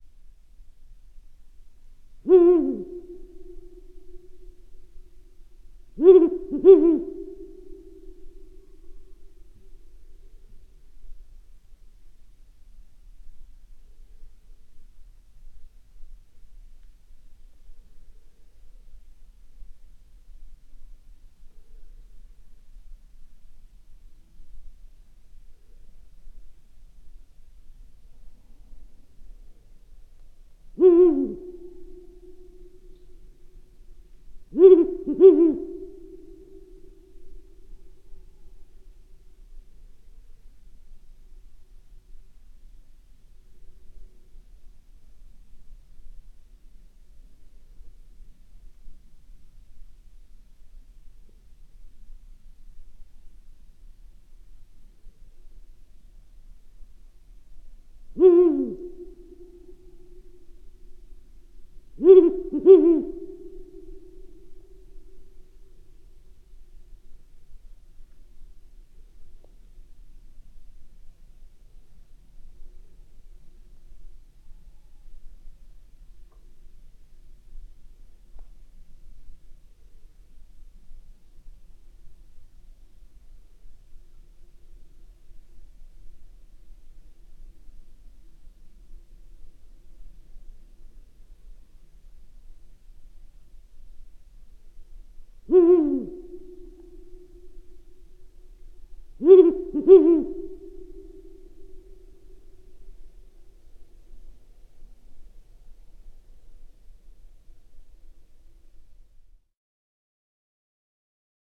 Ural Owl
4-01-Ural-Owl-Compound-Hooting-Of-Male.wav